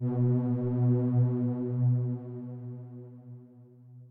b_basspad_v127l1o3b.ogg